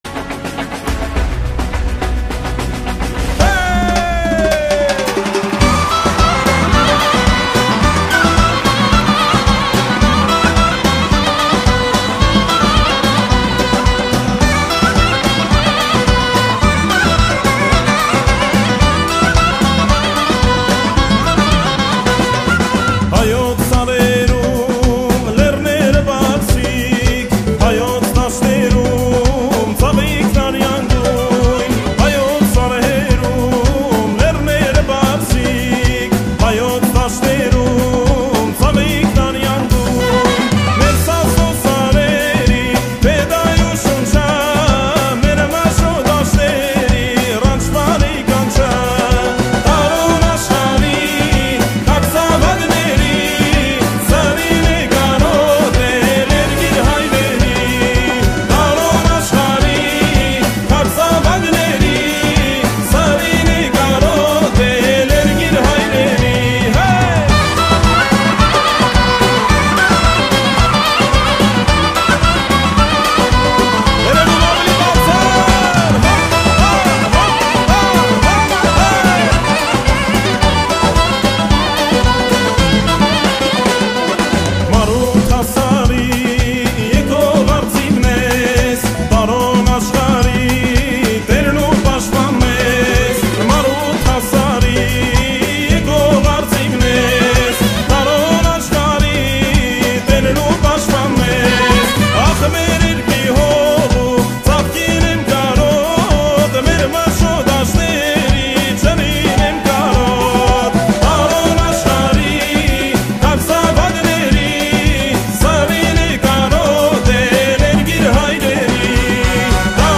Армянская музыка